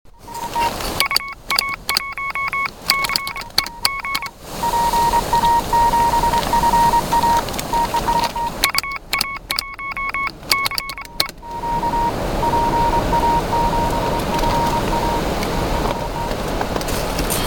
Участвовал в "Полевом дне" честной QRP/p мощностью.
Ипару аудио, что бы проникнуться атмосферой теста, кстати вете был такой силы, что на аудио слышно.